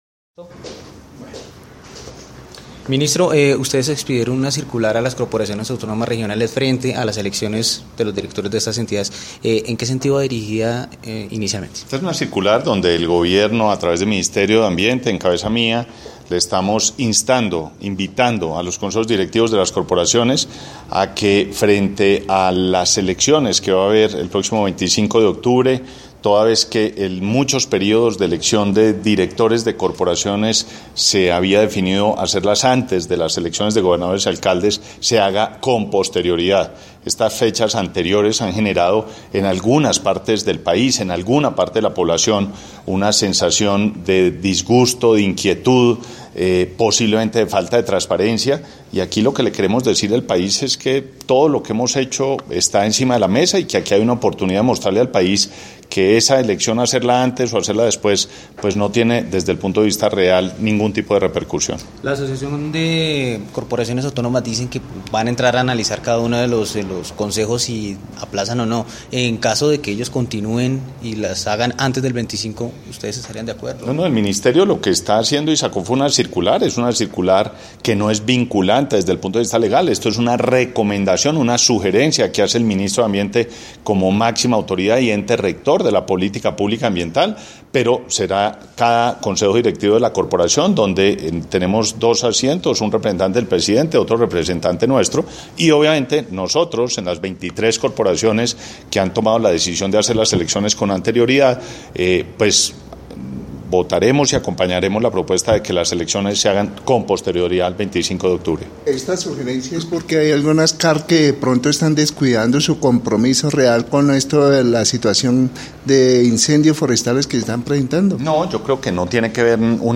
Declaraciones del Ministro de Ambiente y Desarrollo Sostenible, Gabriel Vallejo López